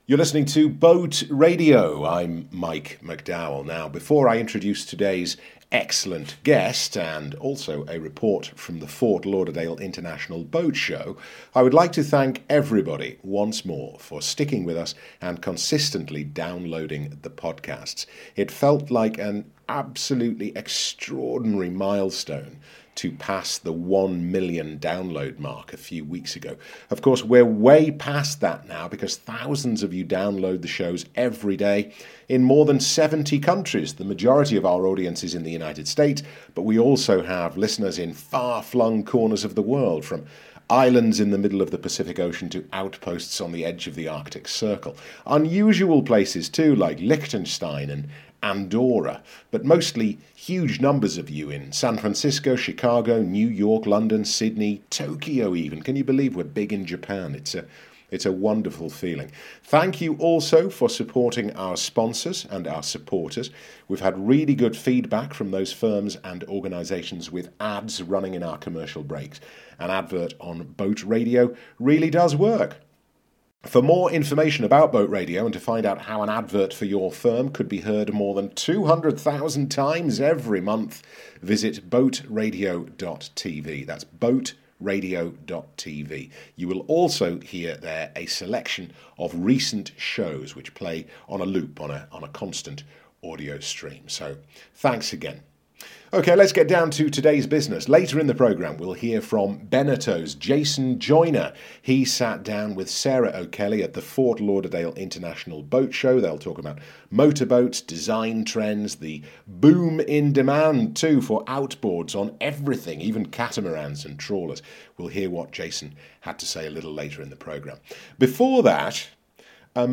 while we wait to arrange and schedule that interview